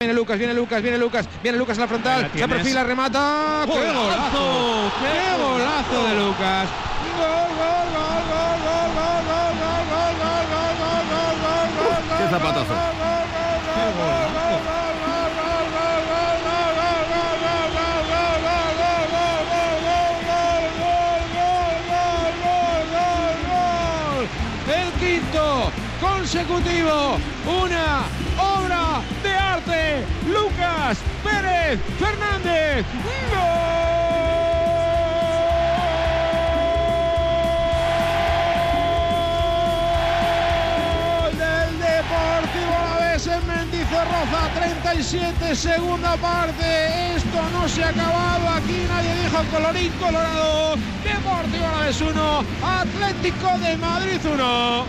Audio: Aquí puedes escuchar la narración del golazo de Lucas Pérez que sirvió para empatar el encuentro ante el Atletico de Madrid en Mendizorrotza (Vitoria)